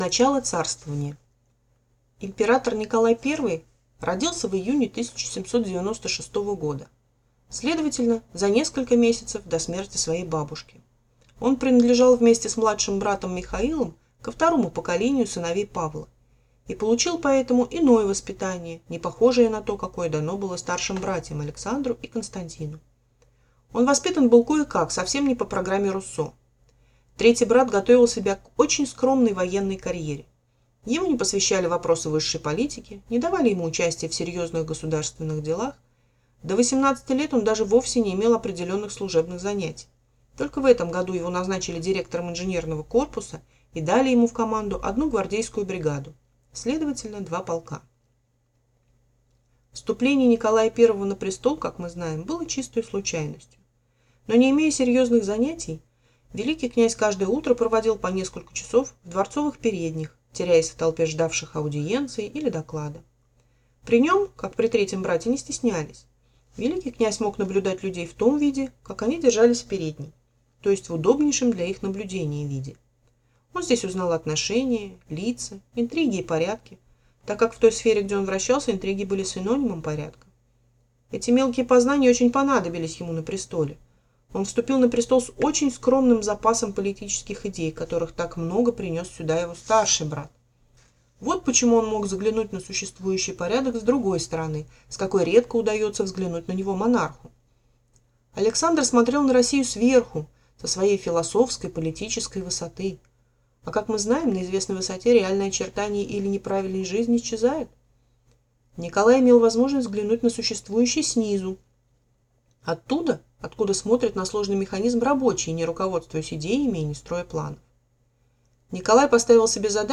Аудиокнига Николай I